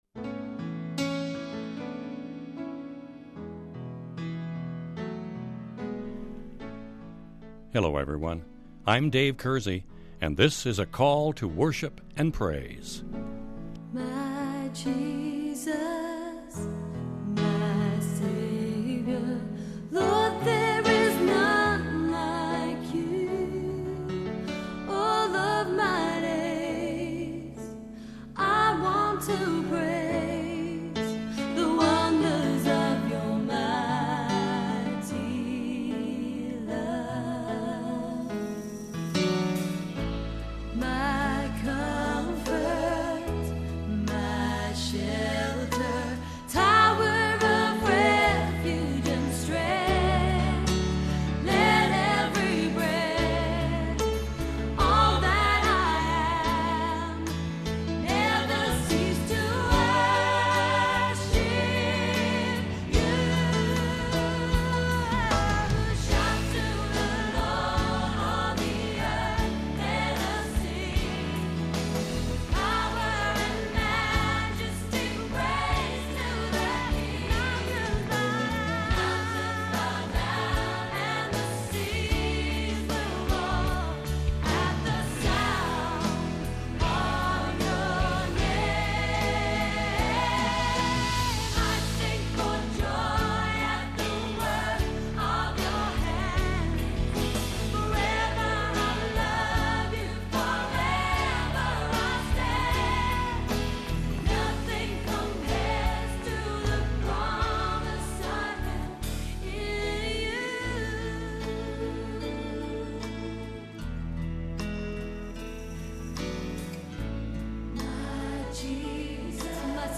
This week’s Devotional Topic This week on Call To Worship we will be talking and singing about restful joy. God has engineered a lifestyle pinned on a keen sense of his provision and faithfulness.